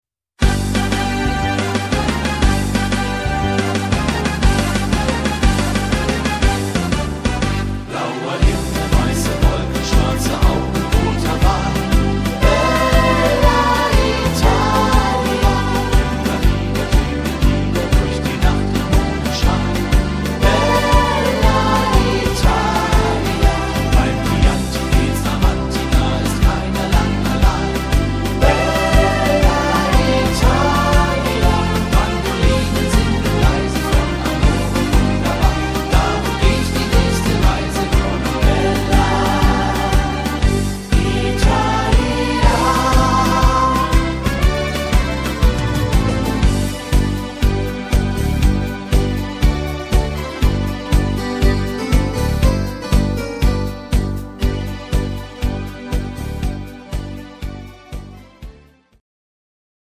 Rhythmus  Tarantella
Art  Deutsch, Schlager 2000er